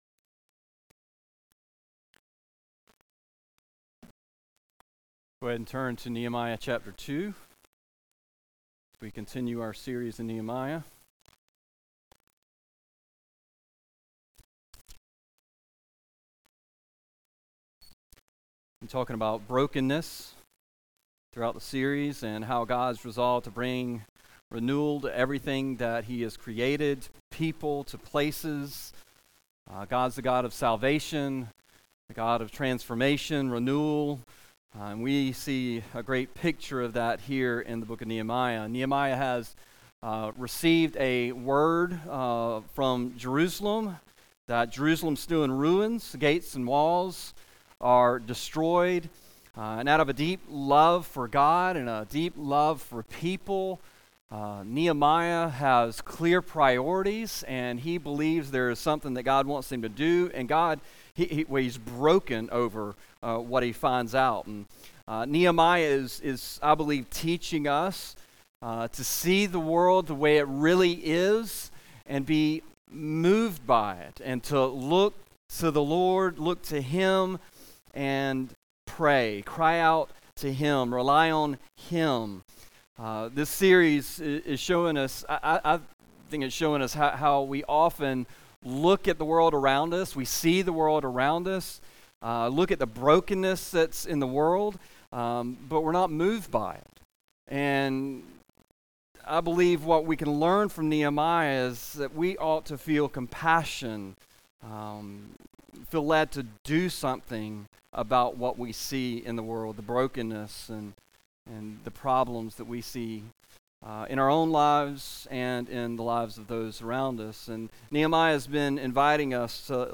A message from the series "Nehemiah."